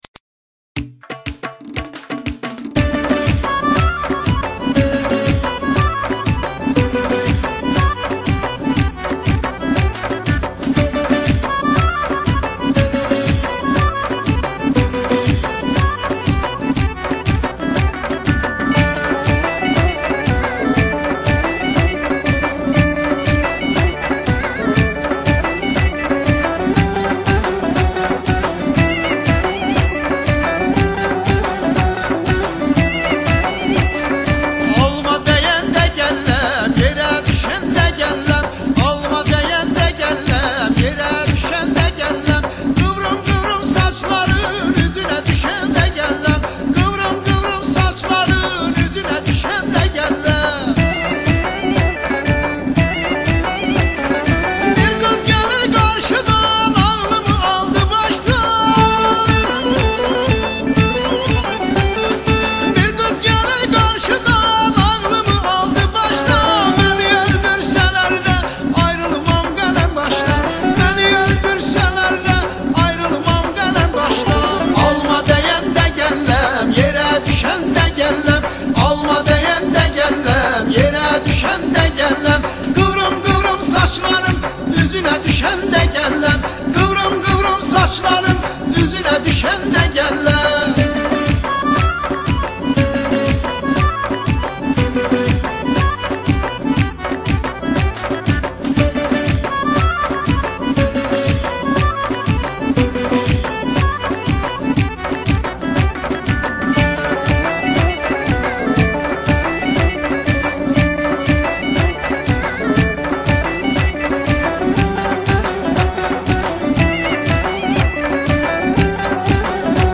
ترکی آذری شاد عروسی